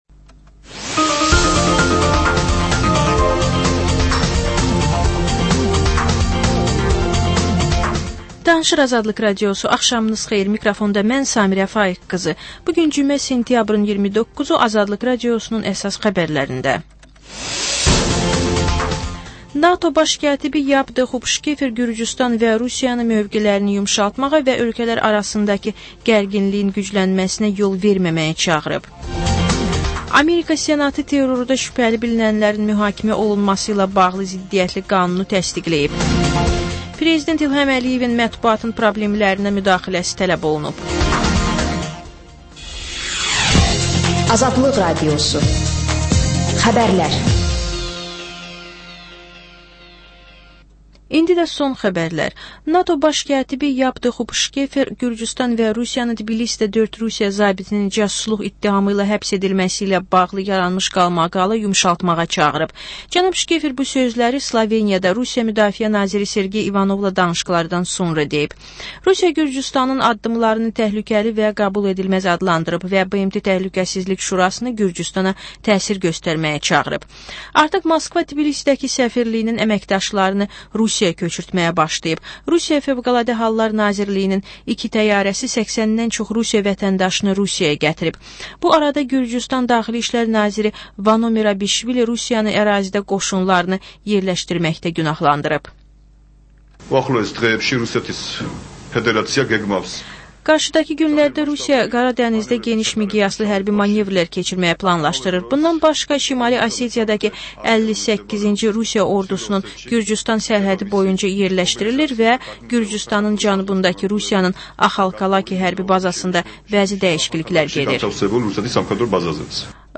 Xəbərlər, reportajlar, müsahibələr. Və: Günün Söhbəti: Aktual mövzu barədə canlı dəyirmi masa söhbəti.